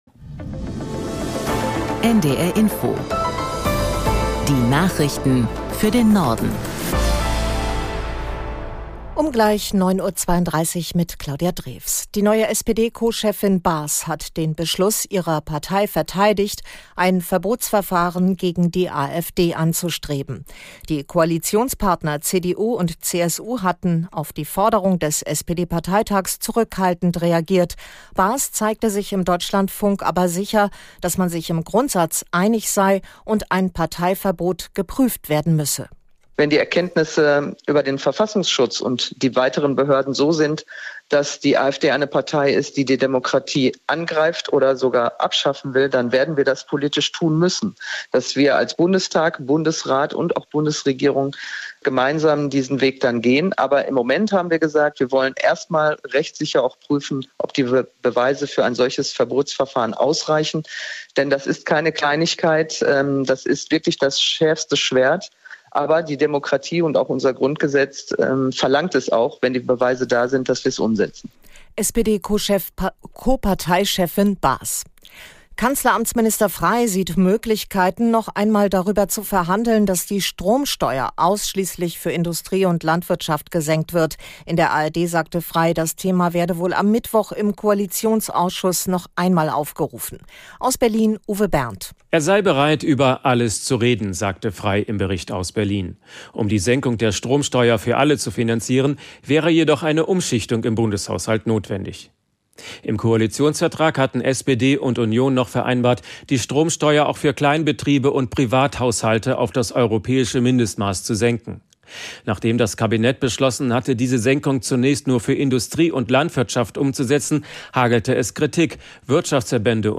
Nachrichten - 30.06.2025